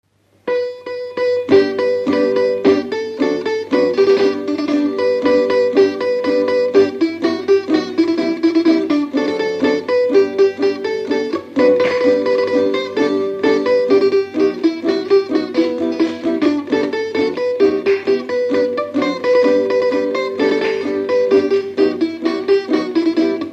Dallampélda: Hangszeres felvétel
Alföld - Bács-Bodrog vm. - Mohol
), tambura (basszprím)
Műfaj: Oláhos
Stílus: 7. Régies kisambitusú dallamok